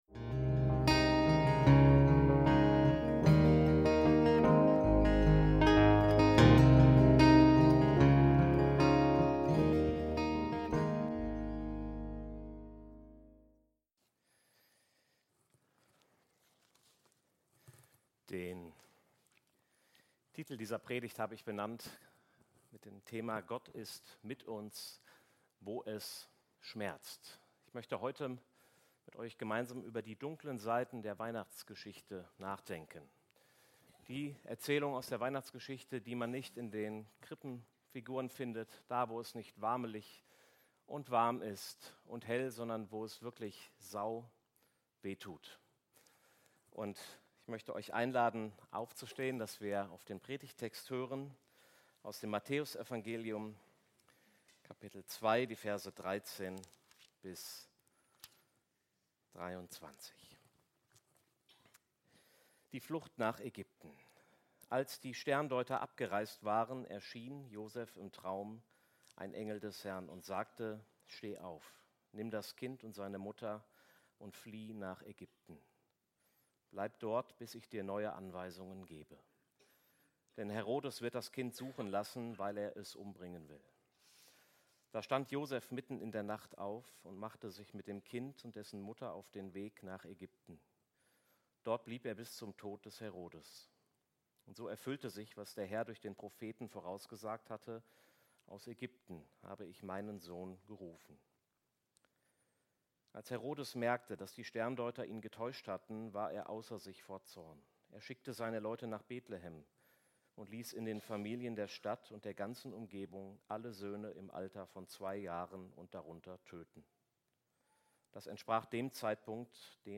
Gott ist mit uns auch in den schweren Tagen - Predigt vom 28.12.2025